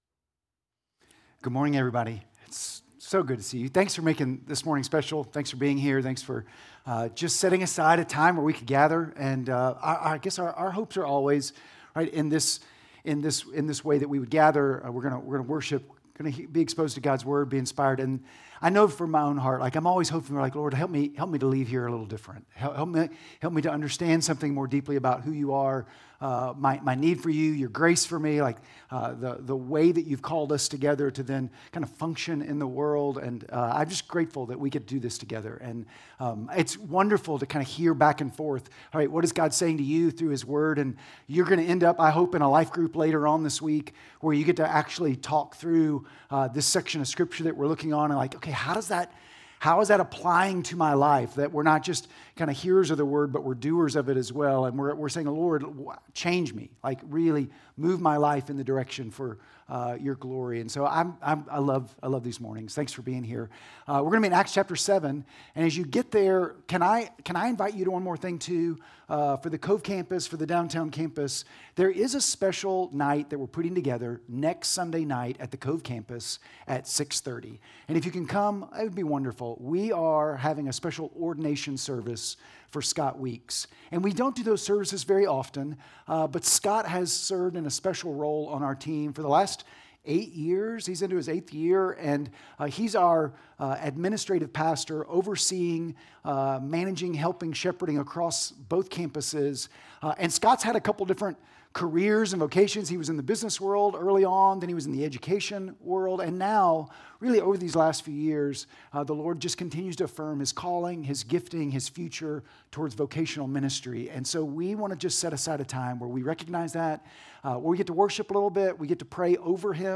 Sermon Audio…